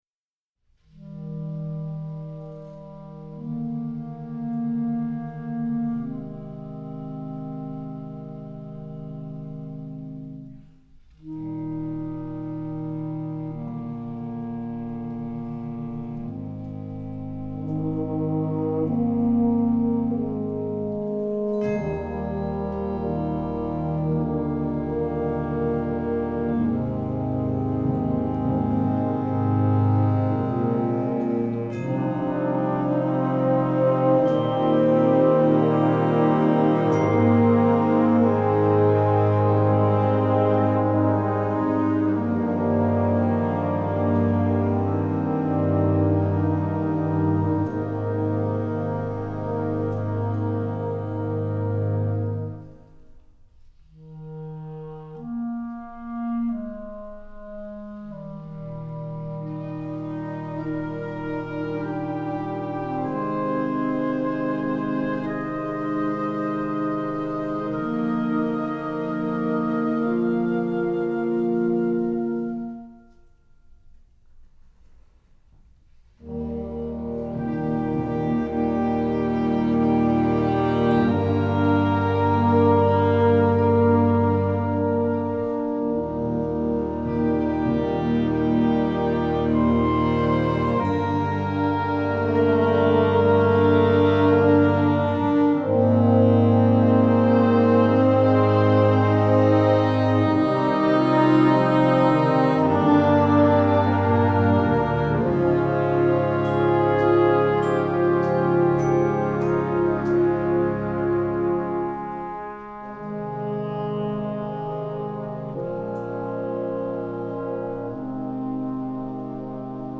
Partitions pour ensemble flexible, 7-voix + percussion.